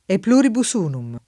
[lat. H pl 2 ribu S 2 num ] frase («da molti uno solo») — motto degli Stati Uniti d’America